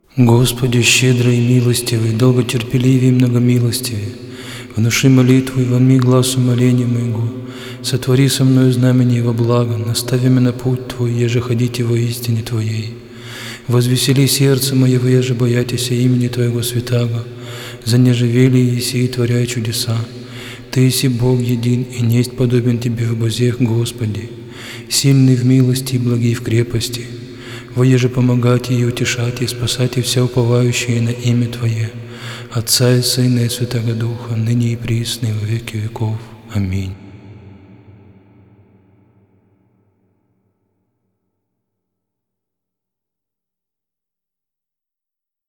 Псалмы царя-пророка Давида Диск 2  12 Прослушай всички композиции от този стил музика Прослушай всички композиции от този стил музика в случаен ред Проповед
12-Молитва.mp3